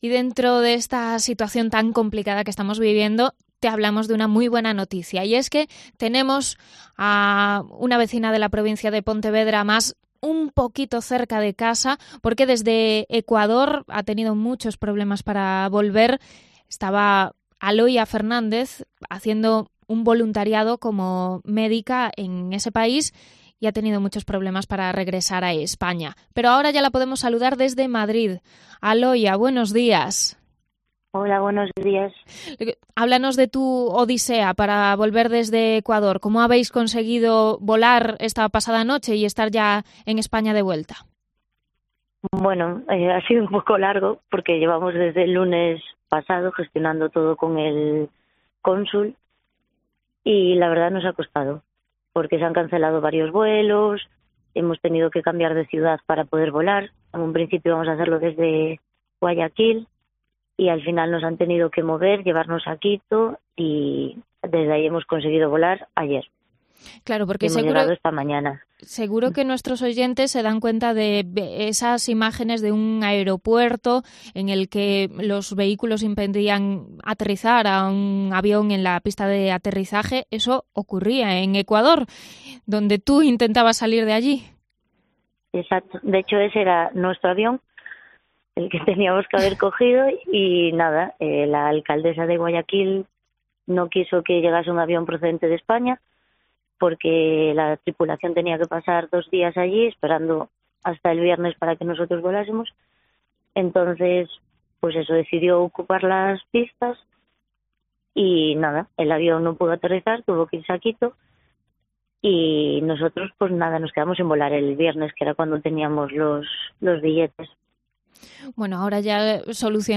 Entrevista a una gallega de regreso a España tras una semana sin poder volar desde Ecuador